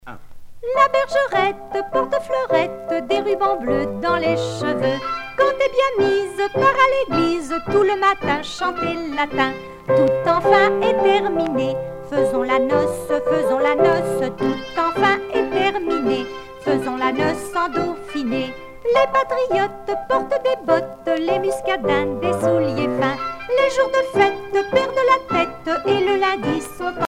danse : rondes enfantines (autres)
Pièce musicale éditée